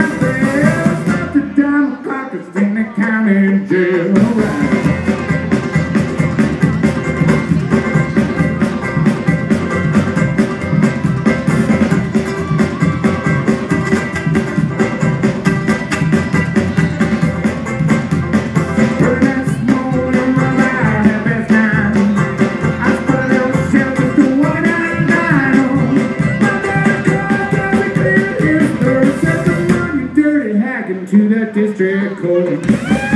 The sounds of the Little Woody beer fest.